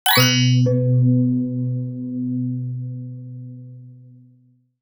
UI_SFX_Pack_61_15.wav